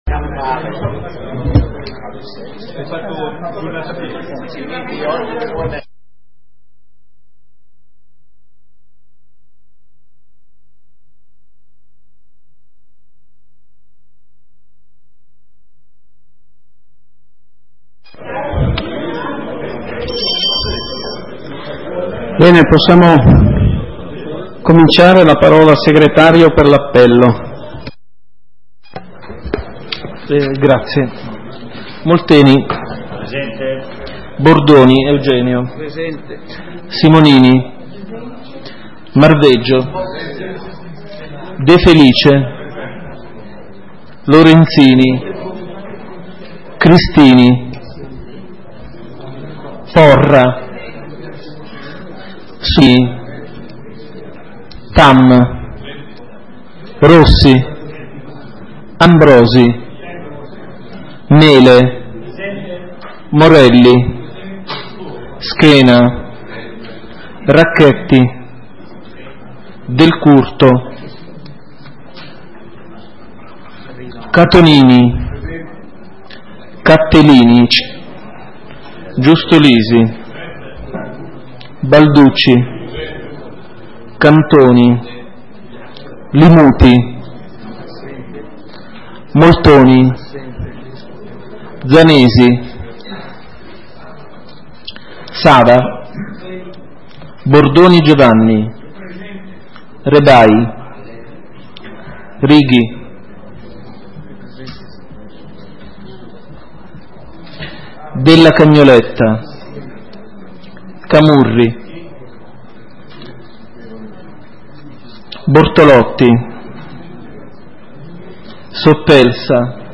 Seduta consiglio comunale del 29 giugno 2012 - Comune di Sondrio